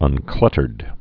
(ŭn-klŭtərd)